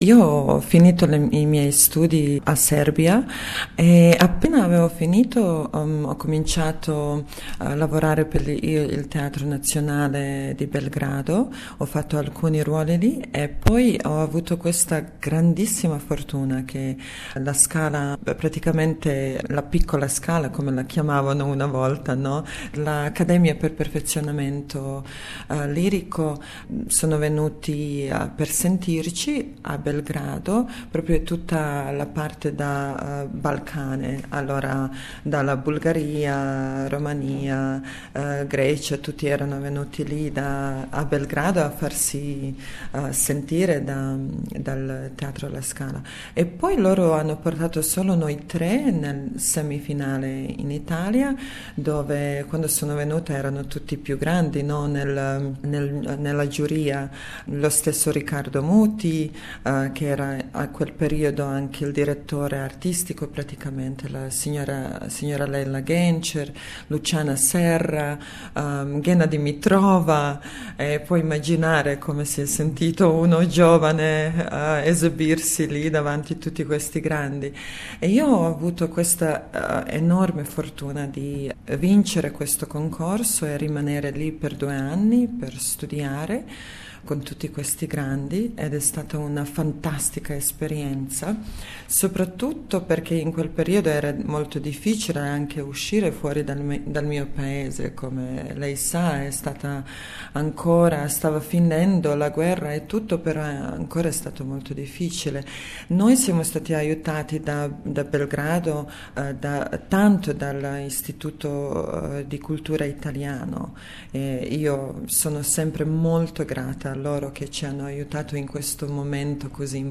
In this interview she talks of her love for opera and her dreams for the future.